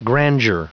Prononciation du mot grandeur en anglais (fichier audio)
Prononciation du mot : grandeur